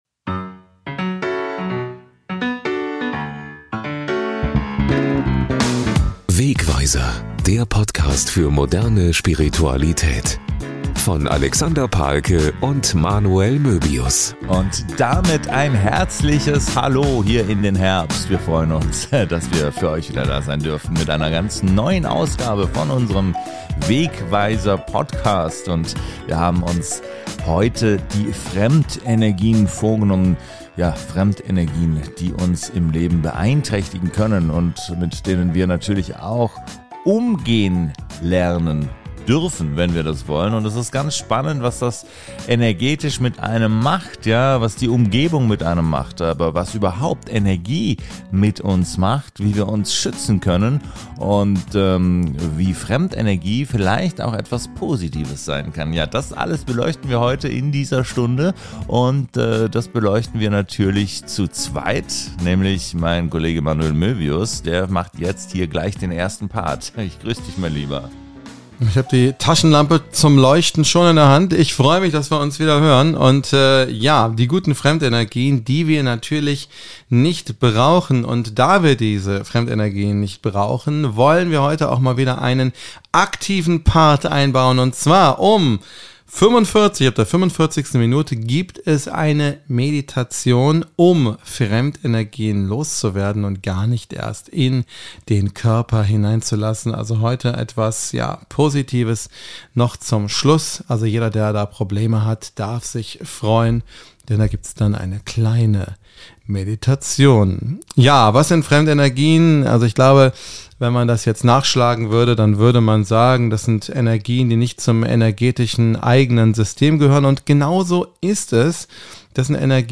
erläutern zahlreiche Beispiele und runden die Folge mit einer geeigneten Meditation ab.